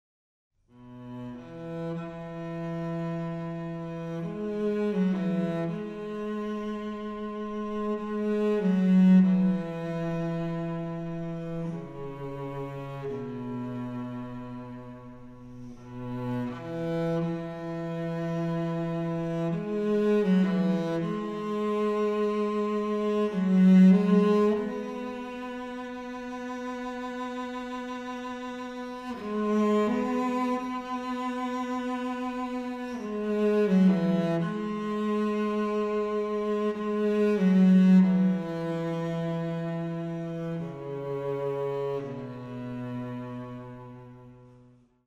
Recording Location 덴덴홀 에로라(일본 사이타마현 마츠부시마치)
첼로와 하프로 연주된 이 곡은 한층 더 곡의 깊은 맛을 느끼게 해줍니다.